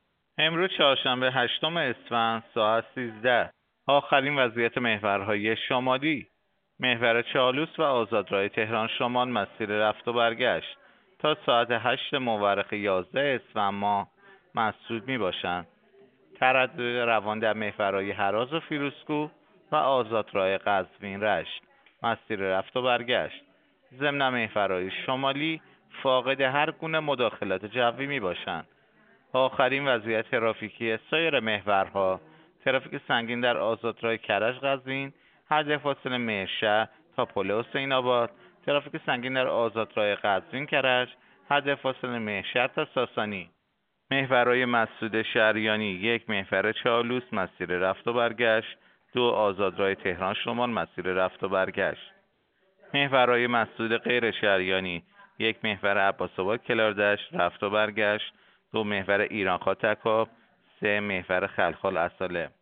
گزارش رادیو اینترنتی از آخرین وضعیت ترافیکی جاده‌ها ساعت ۱۳ هشتم اسفند؛